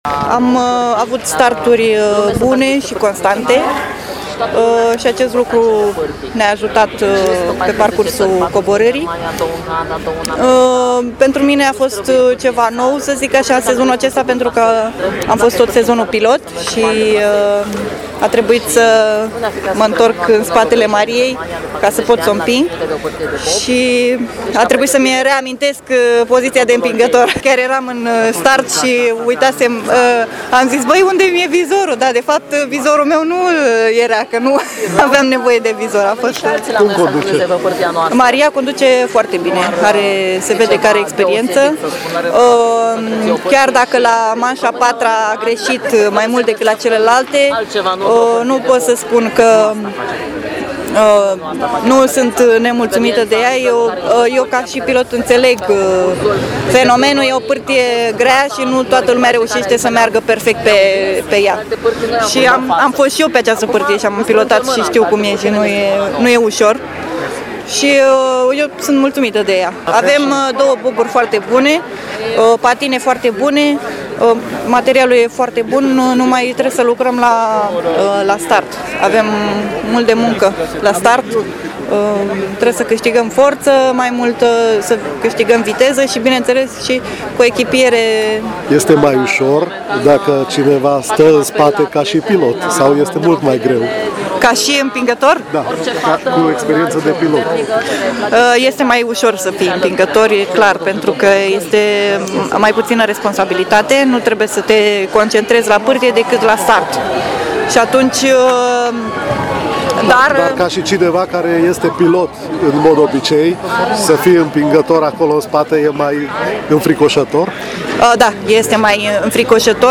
Cele două românce au încheiat cele 4 manșe cu timpul de 3 minute 25 de secunde și 53 de sutimi. Interviuri cu cele două reprezentante ale României